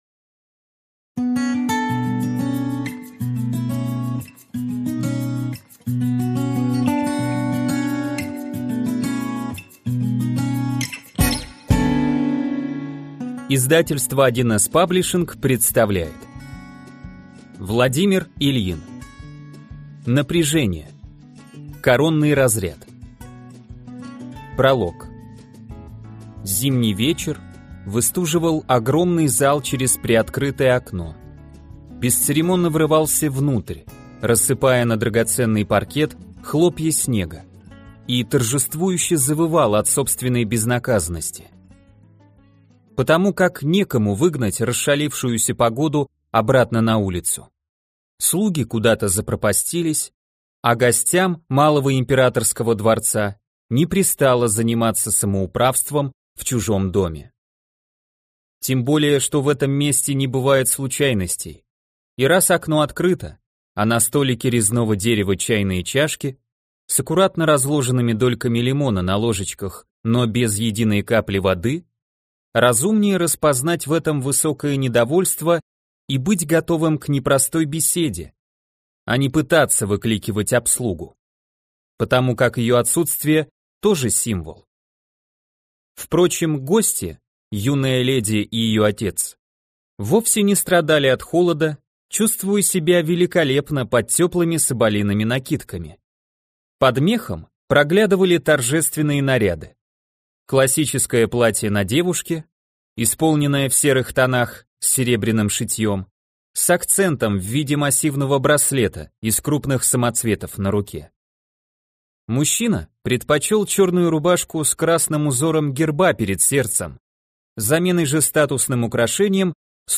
Аудиокнига Напряжение. Коронный разряд - купить, скачать и слушать онлайн | КнигоПоиск